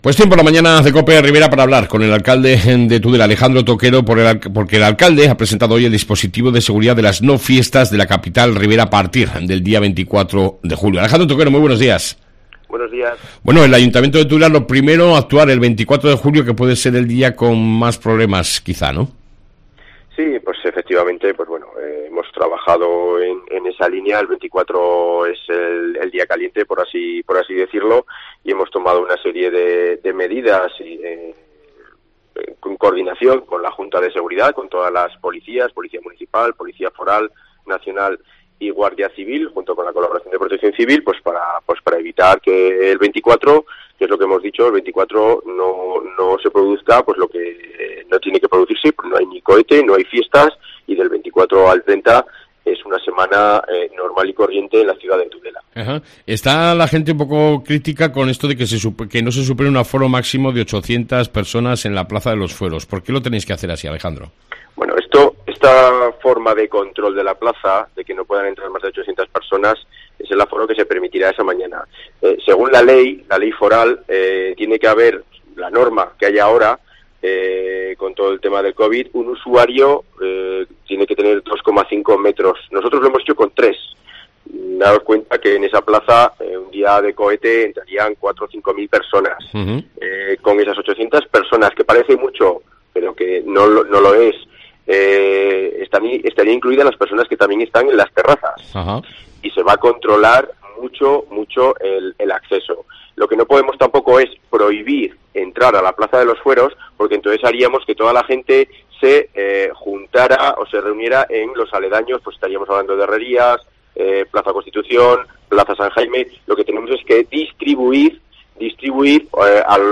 AUDIO: Entrevista con el Alcalde de Tudela Alejandro Toquero.